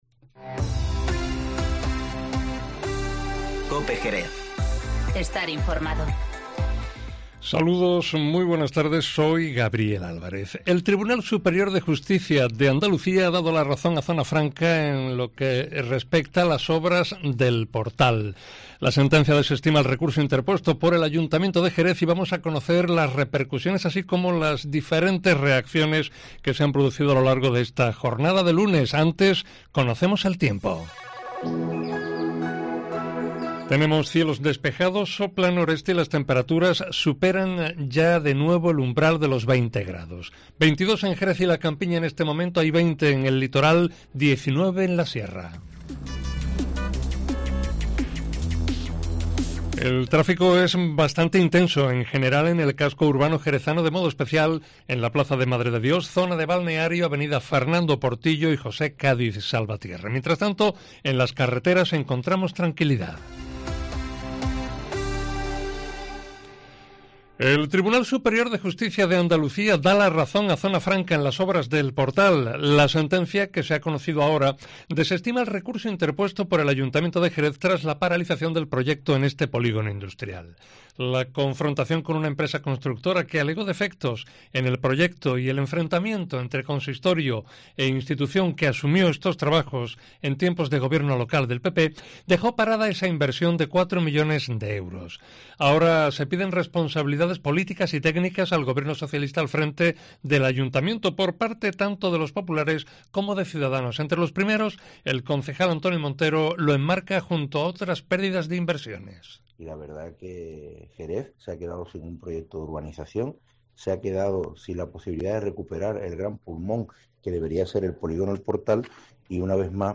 Informativo Mediodía COPE en Jerez 10-02-20